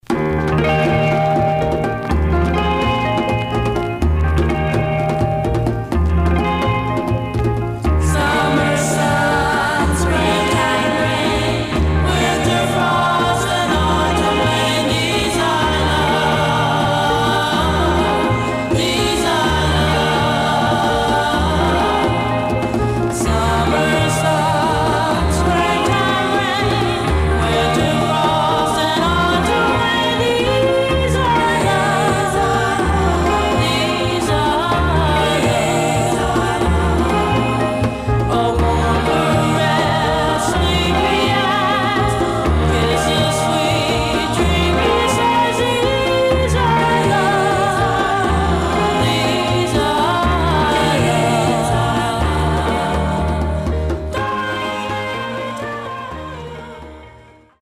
Stereo/mono Mono
Black Female Group